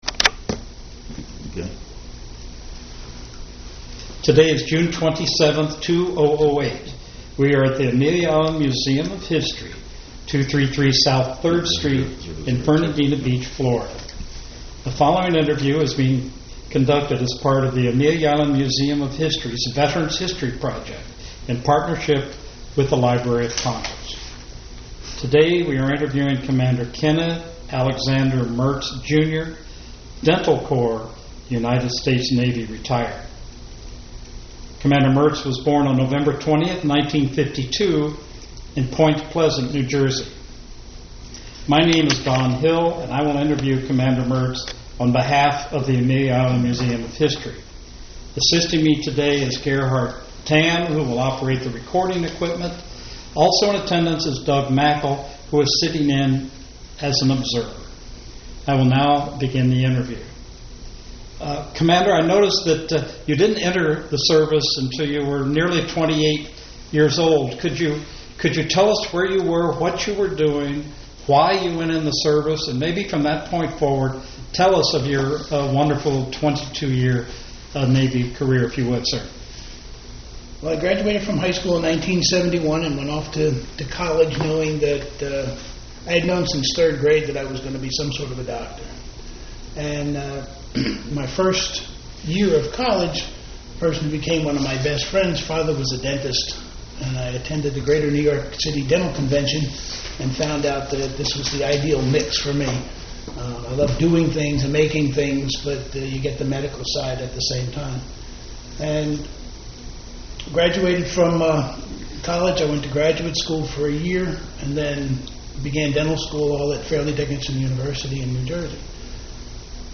Oral History Recording